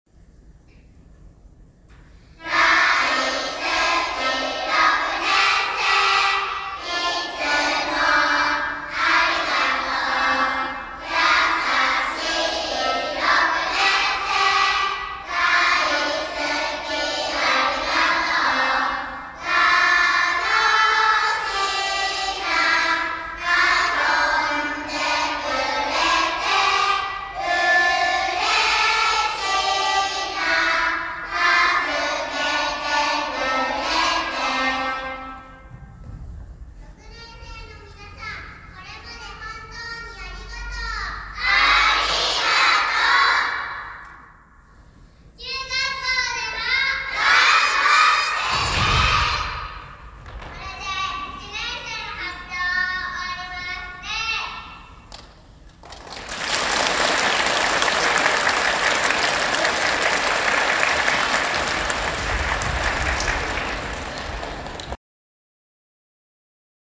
６年生感謝の気持ちを込めて『ありがとう♡集会』が行われました。
１年生は、寸劇と『子犬のマーチ』の替え歌で、大好きな６年生のお兄さん、お姉さんに歌のプレゼント。